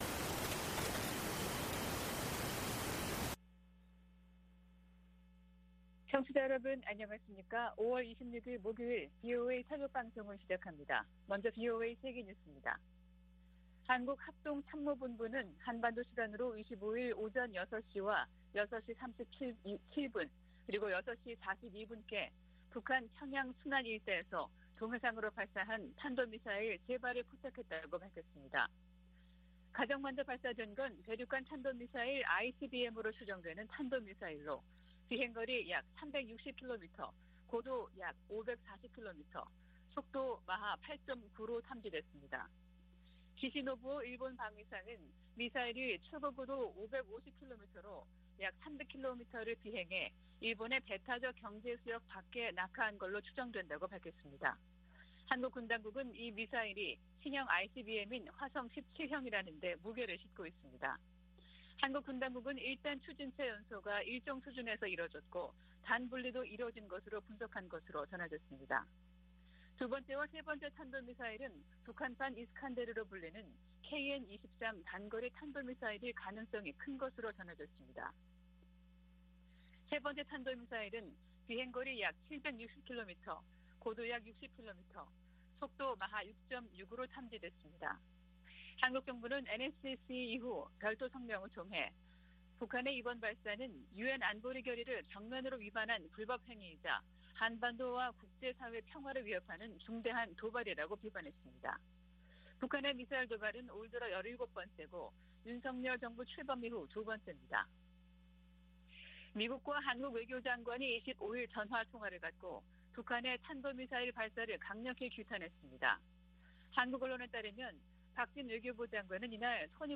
VOA 한국어 '출발 뉴스 쇼', 2022년 5월 26일 방송입니다. 북한이 ICBM을 포함한 탄도미사일 3발을 동해상으로 발사했습니다.